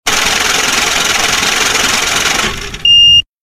moneycounter.mp3